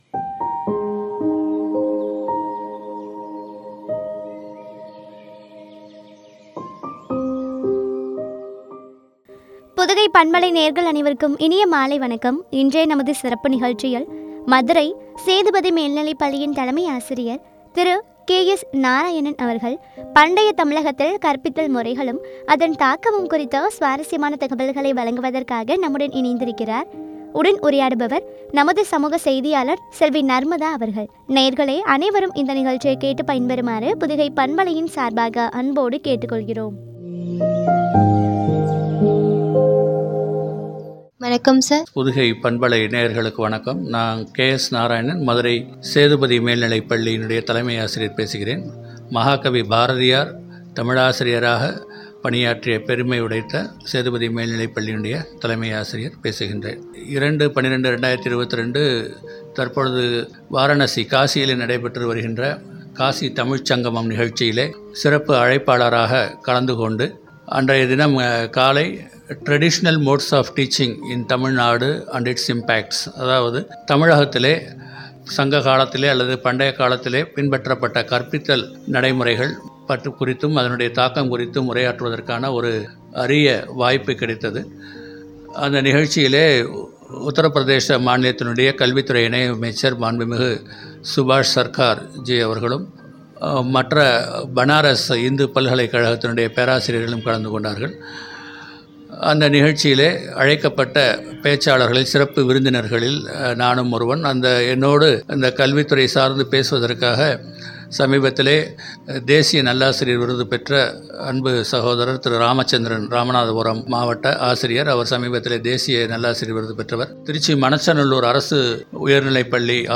அதன் தாக்கமும் பற்றிய உரையாடல்.